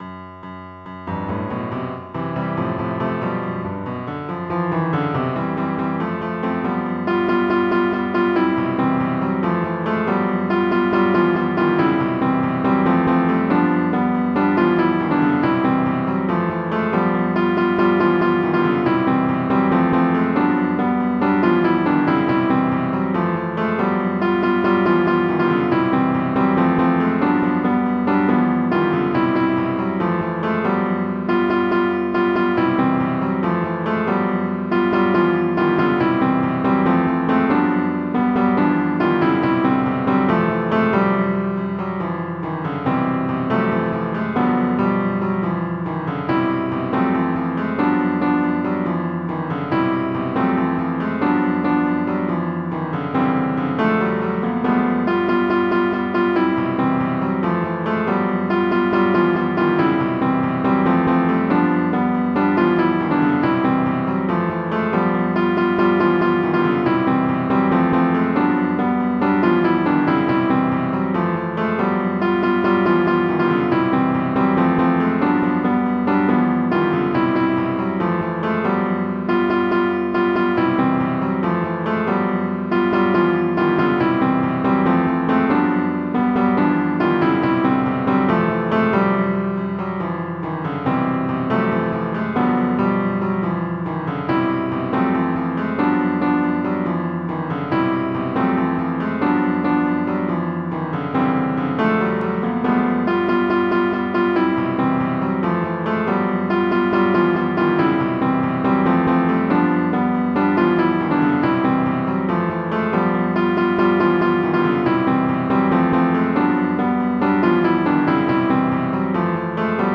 MIDI Music File
General MIDI (type 0)